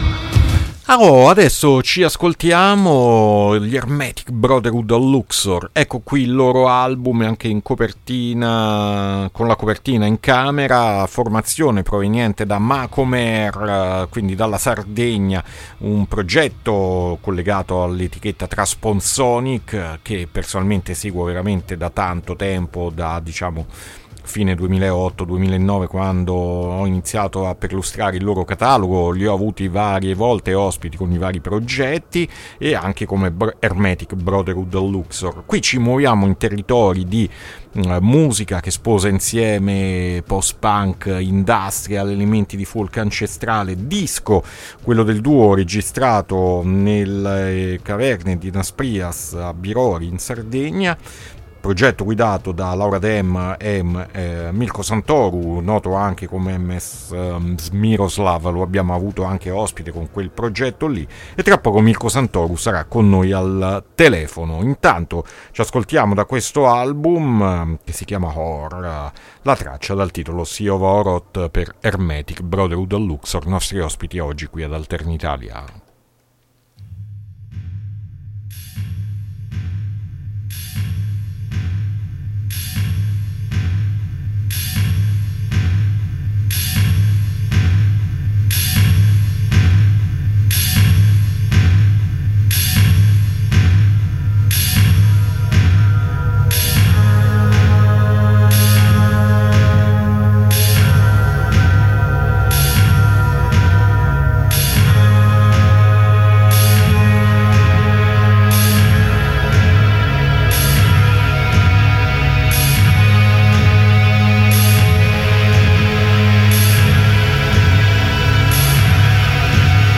INTERVISTA HERMETIC BROTHERHOOD OF LUX-OR AD ALTERNITALIA 5-1-2024